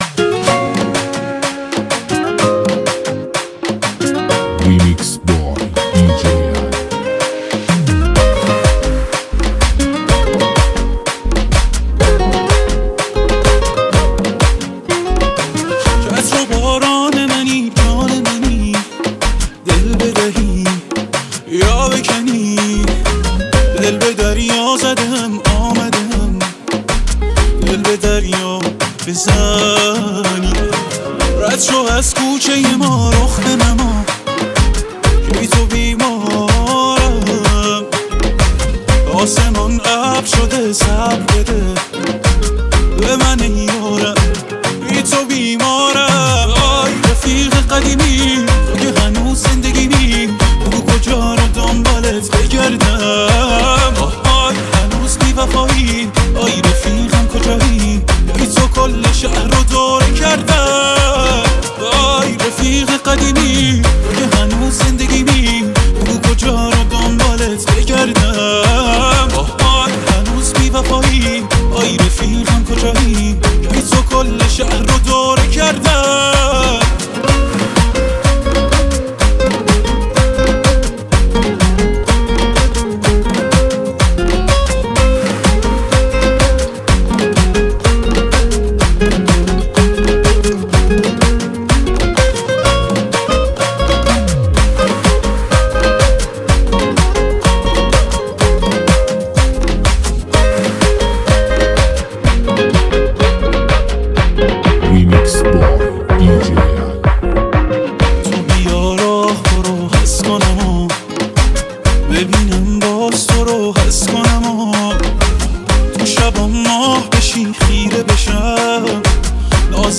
موسیقی احساسی و پرمعنا برای لحظاتی پر از آرامش و نوستالژی.